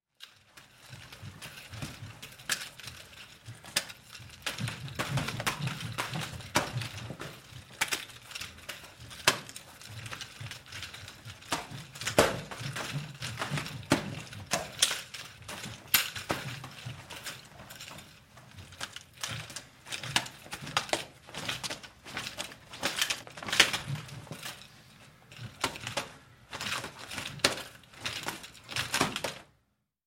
Солдат отрабатывает приемы с нунчаками